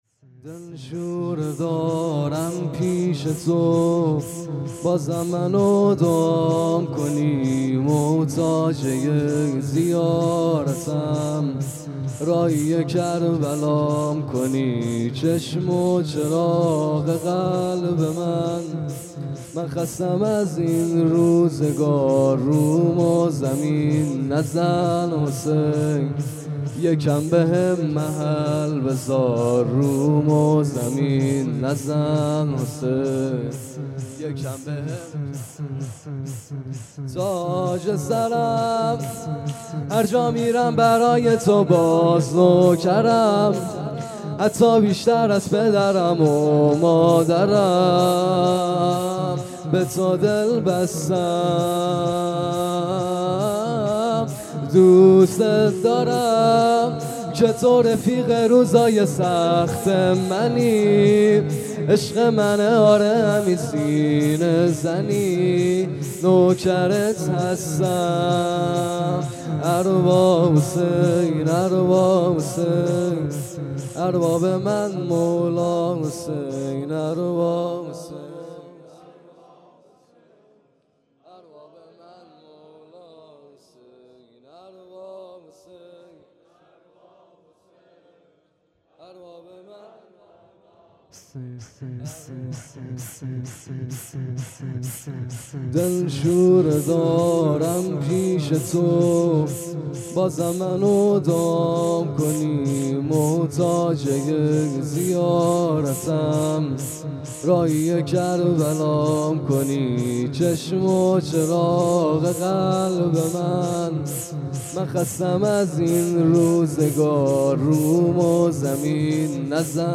شور | دلشوره دارم پیش تو
گزارش صوتی شب چهارم محرم 96 | هیأت محبان حضرت زهرا سلام الله علیها زاهدان